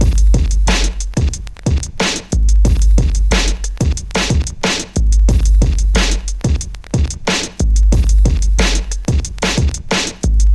Drum beat Free sound effects and audio clips
91 bpm vinyl hiphop drumloop.wav
Boom bap drumloop sampled from old vinyl record
91_bpm_vinyl_hiphop_drumloop_12C.wav